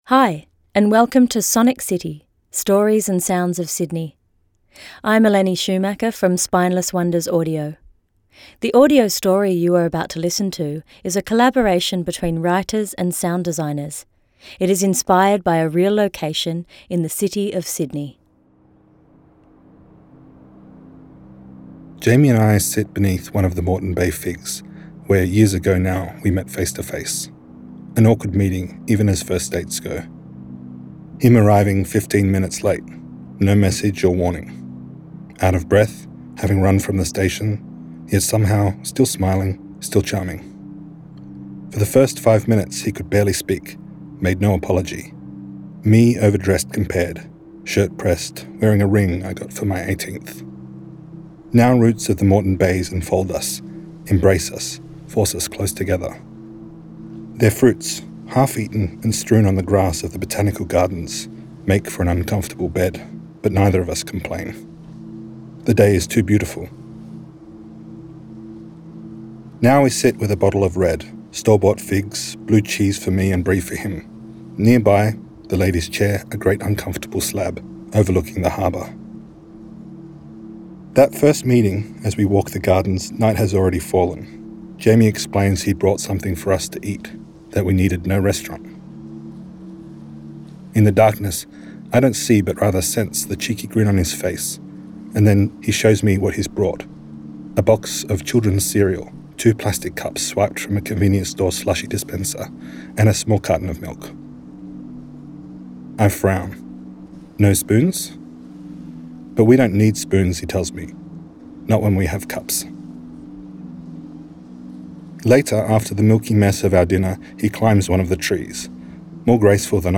Royal Botanic Gardens, Mrs Macquaries Rd, Sydney
Sonic City Sydney is a series of audio-stories, each under five minutes in length, produced by Spineless Wonders and Echidna Audio and funded by the City of Sydney.